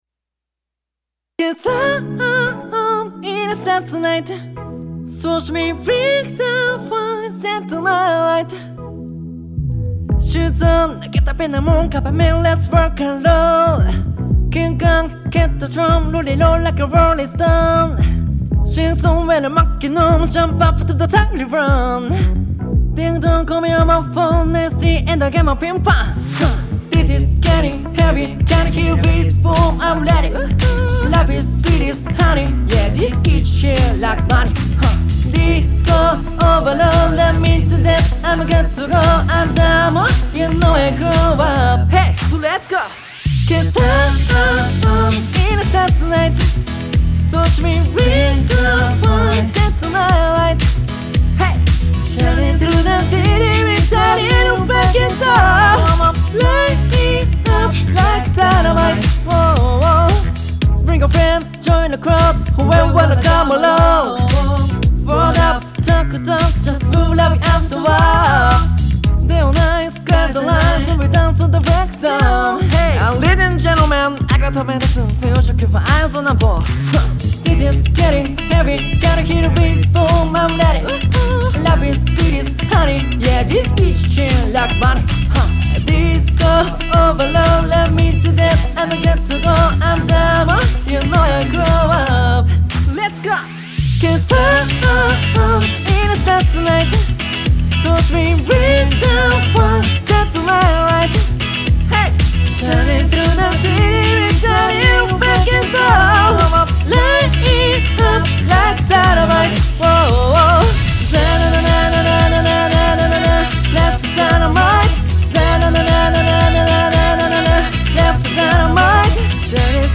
サンプル歌みたミックス
女性Vo